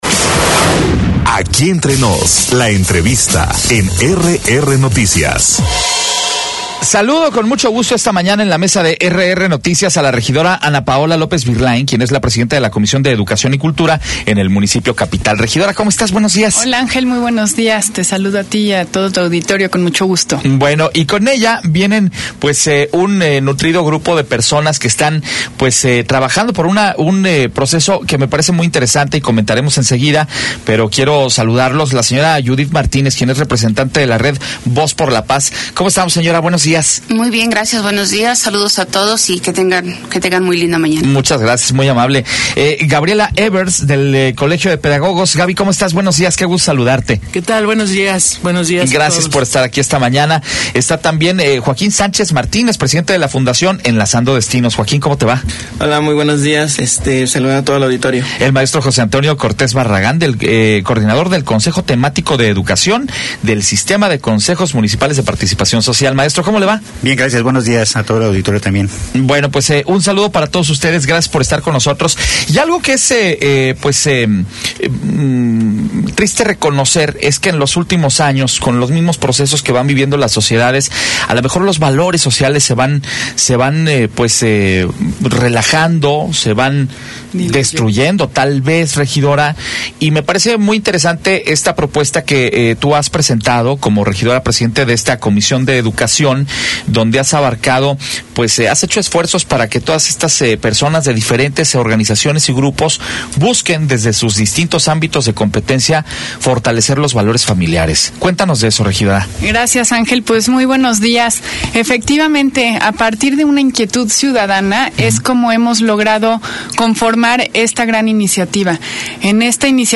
La regidora Paola López Birlain invita a la conferencia «Educar para vivir, educar para convivir»